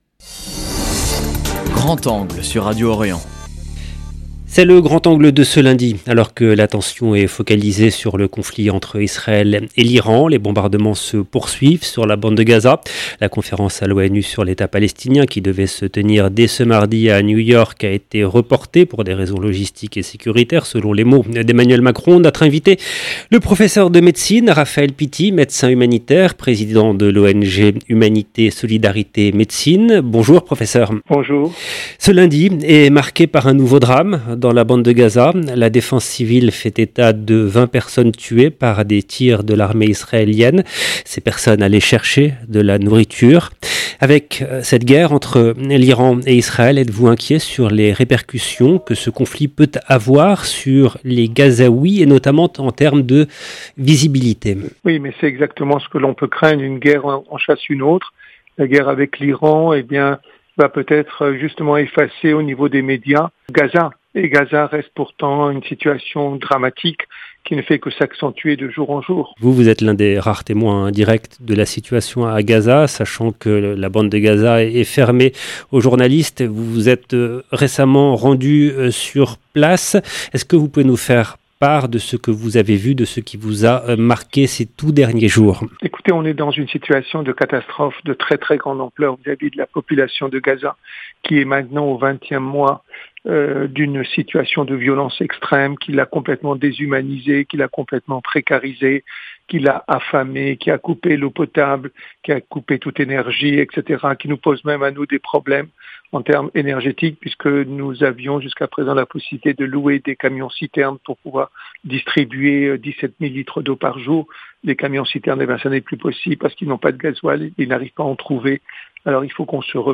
Notre invité : le professeur de médecine